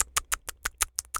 pgs/Assets/Audio/Animal_Impersonations/rabbit_eating_03.wav at master
rabbit_eating_03.wav